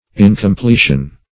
\In`com*ple"tion\
incompletion.mp3